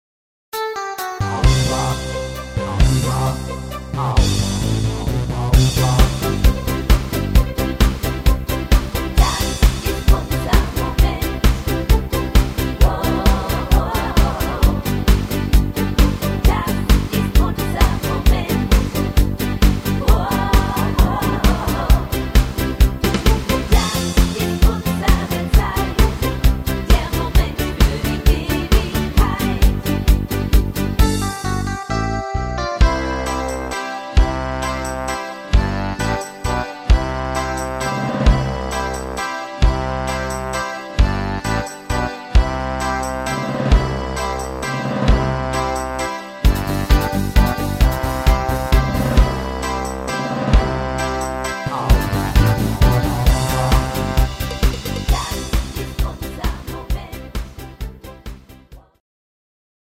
Rhythmus  Disco